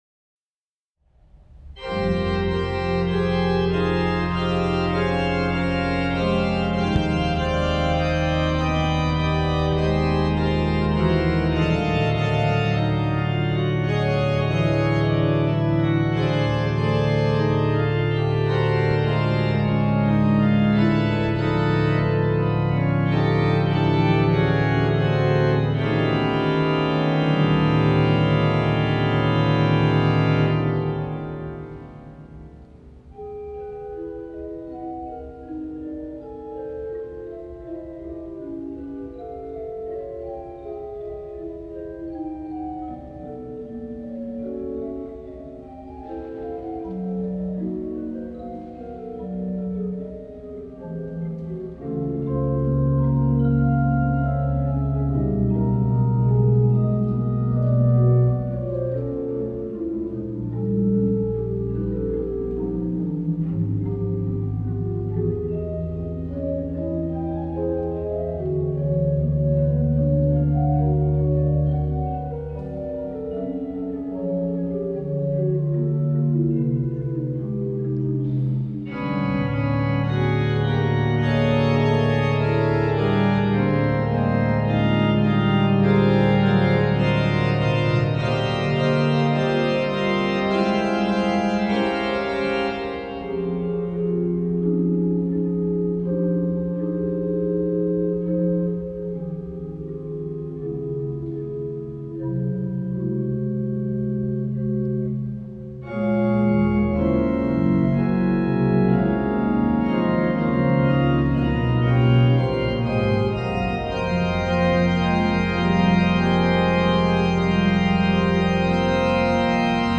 Choralmotette
Für gemischten Chor und Orgel.